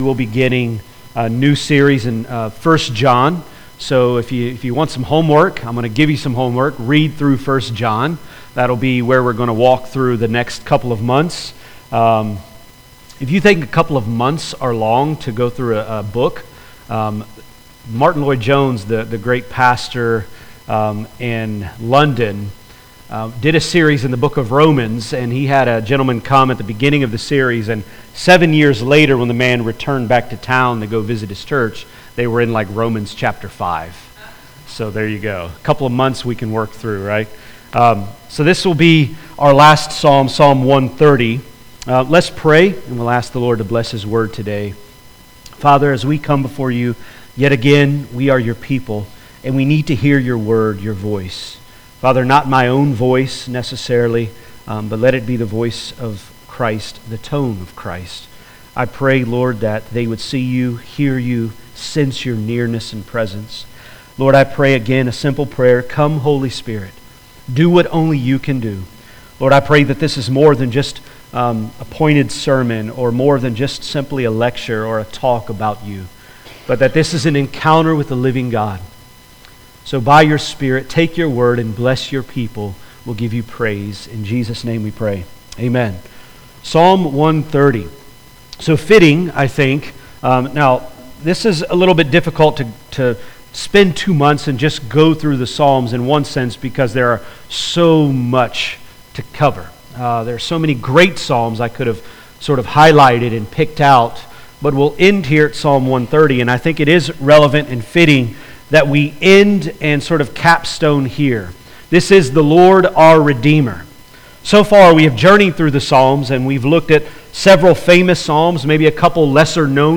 Sermons | Florence Alliance Church